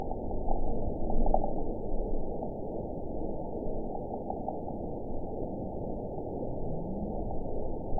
event 916796 date 01/31/23 time 10:00:09 GMT (2 years, 3 months ago) score 9.31 location TSS-AB03 detected by nrw target species NRW annotations +NRW Spectrogram: Frequency (kHz) vs. Time (s) audio not available .wav